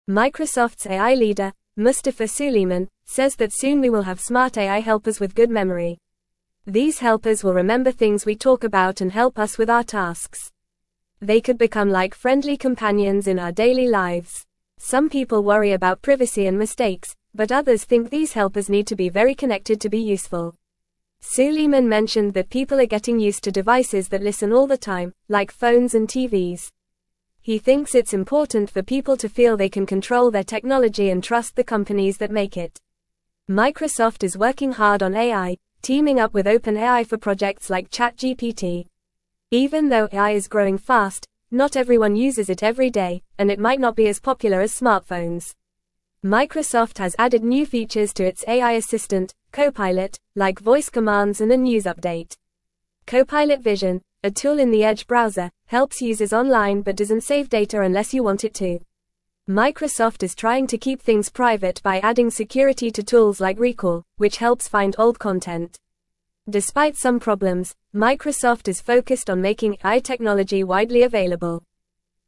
Fast
English-Newsroom-Lower-Intermediate-FAST-Reading-Microsoft-is-Making-Smart-Friends-to-Help-You.mp3